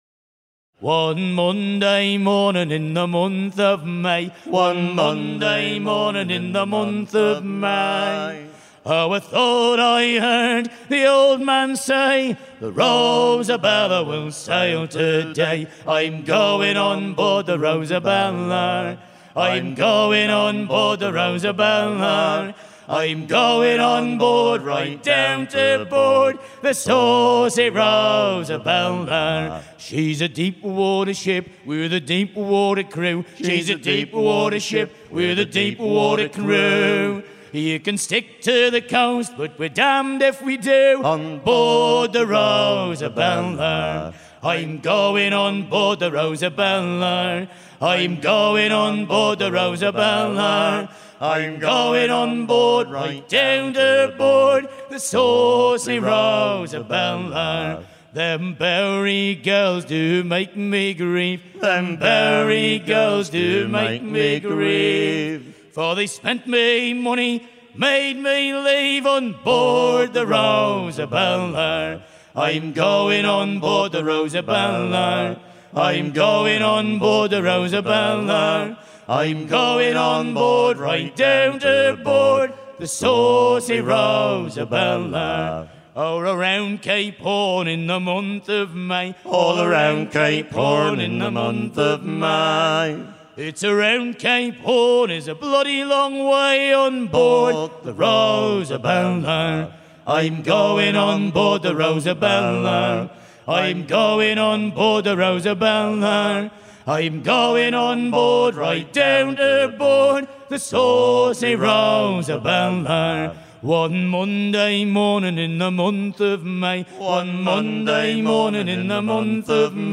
à virer au cabestan
Chants de marins en fête - Paimpol 1999
Pièce musicale éditée